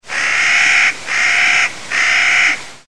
Bird song mp3s files are in the public domain.
clarks-nutcracker.mp3